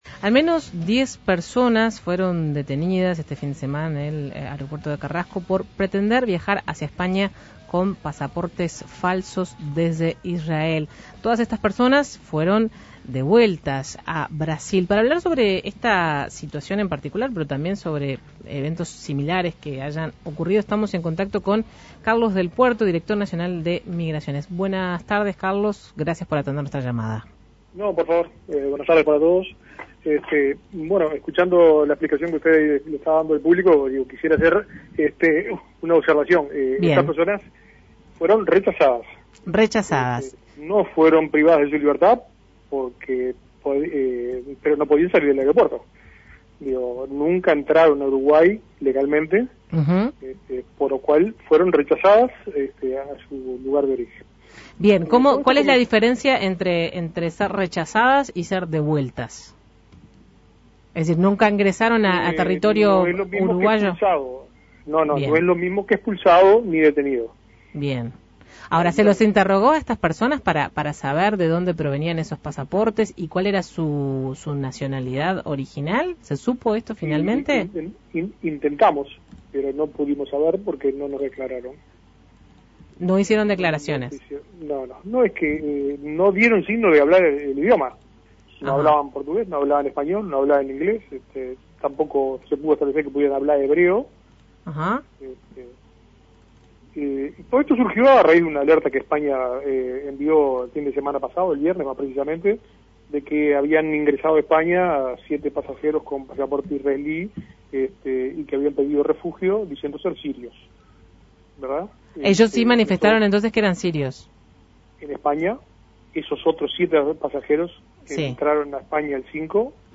Entrevista a Carlos del Puerto